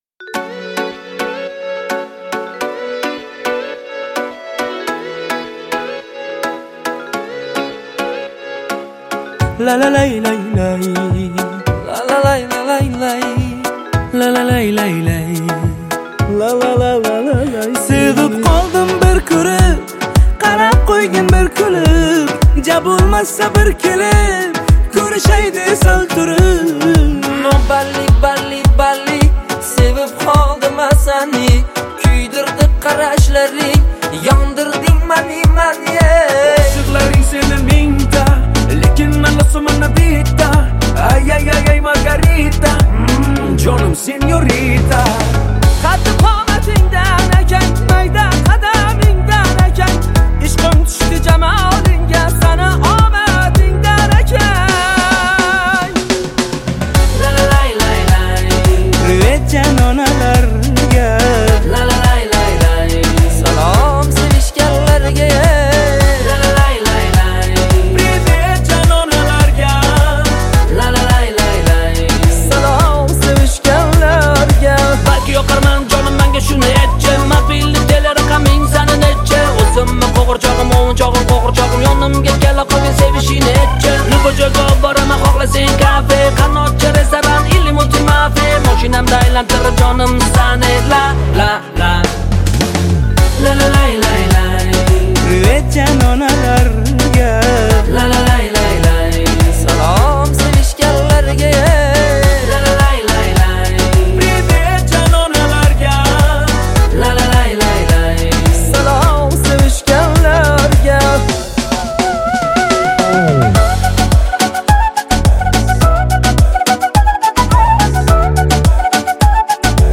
• Жанр: Узбекские песни